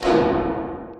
scsm_metallic1w.wav